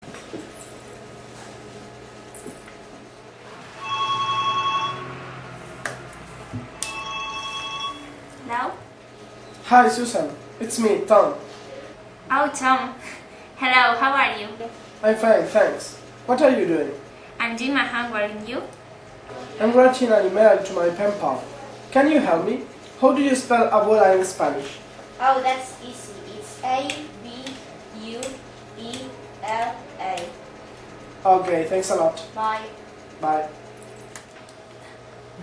Everyday conversations
Chico y chica mantienen una conversación por teléfono.